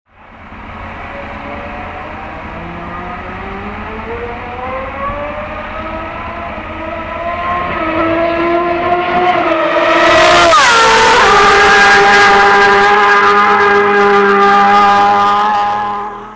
ducatimotogp_24877.mp3